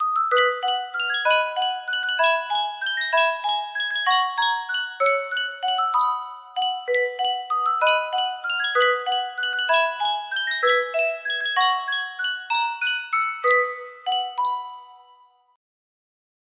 Sankyo Music Box - The Old Rugged Cross T+ music box melody
Full range 60